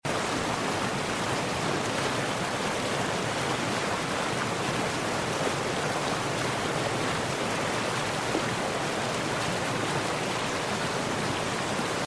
Unwinding along a beautiful creek sound effects free download
Unwinding along a beautiful creek with the soothing sound of a distant waterfall, surrounded by vibrant autumn colors—a perfect moment of peace 😌